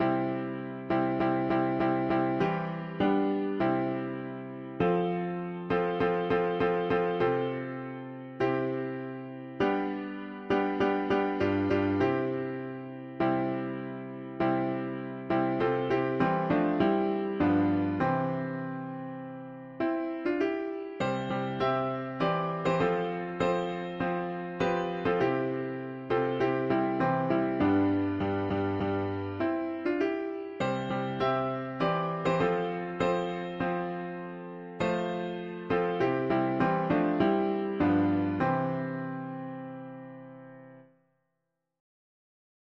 Till… english theist 4part chords
Key: C major